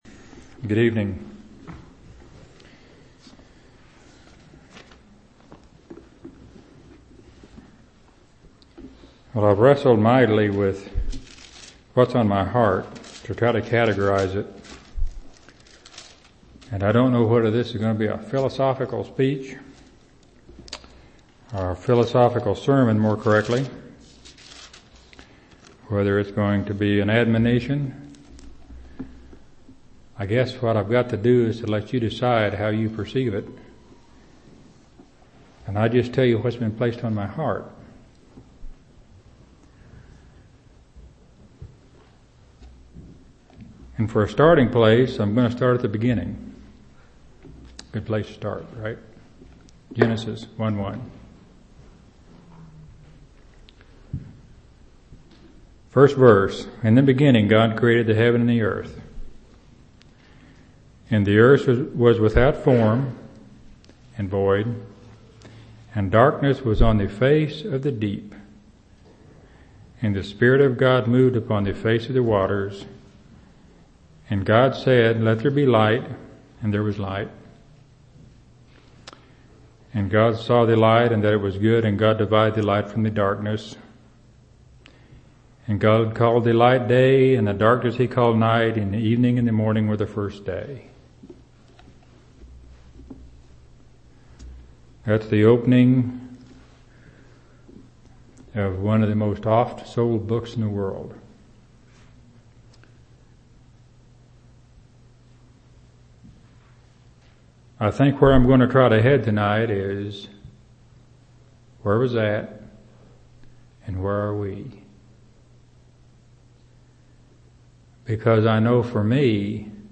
2/20/1994 Location: Temple Lot Local Event